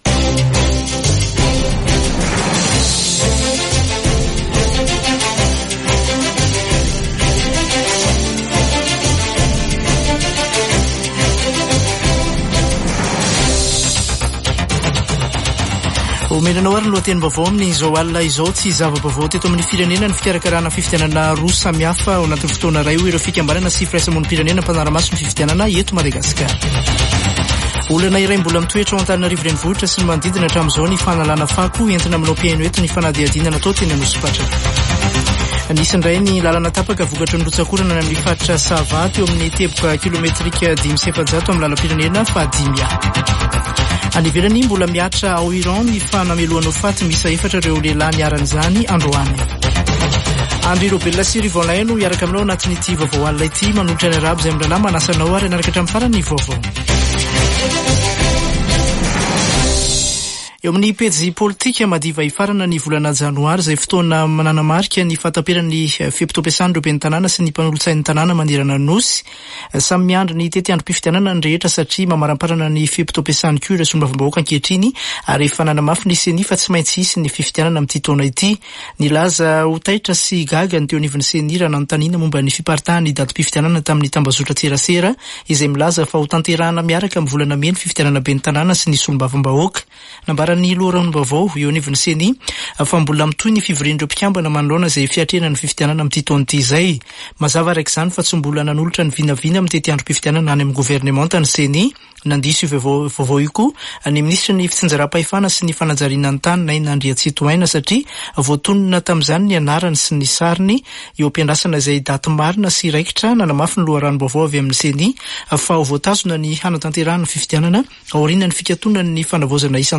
[Vaovao hariva] Alatsinainy 29 janoary 2024